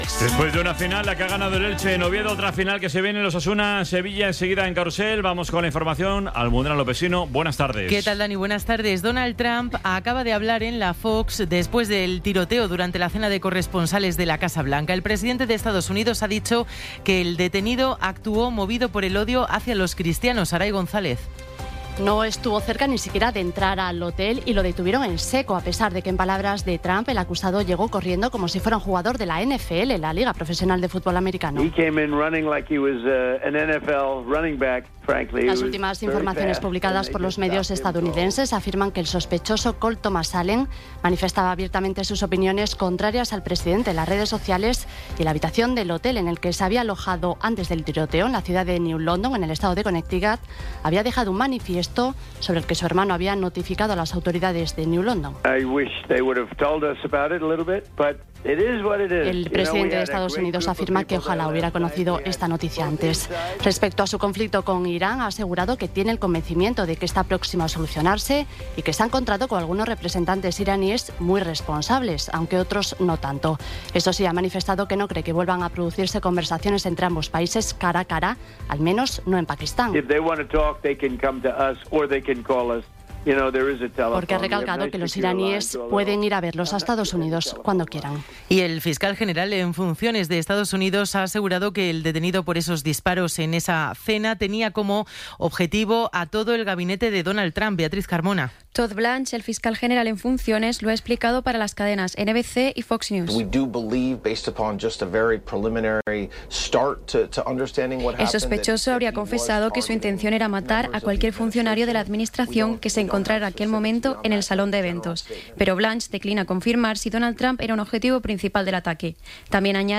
Resumen informativo con las noticias más destacadas del 26 de abril de 2026 a las seis de la tarde.